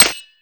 pickaxe_damage_broken.ogg